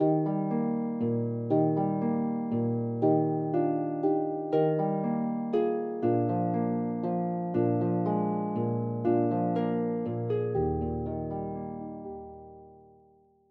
arranged for solo lever and pedal harp